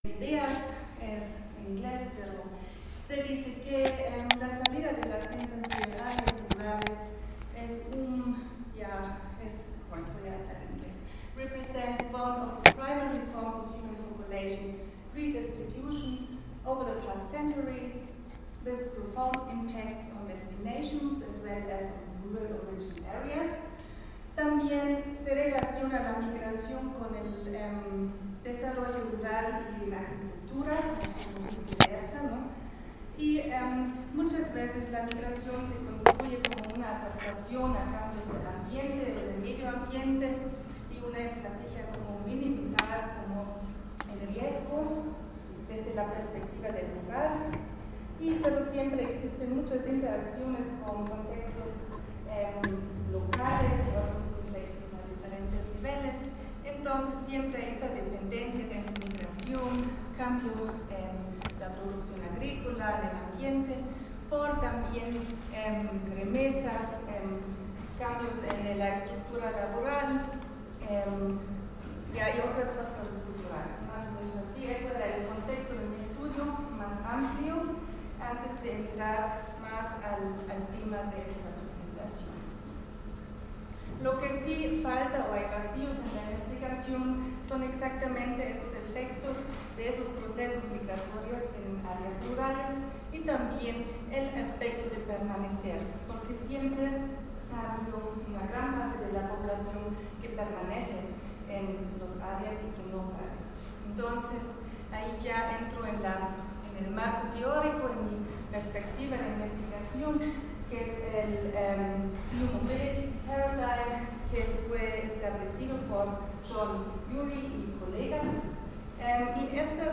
Conversatorio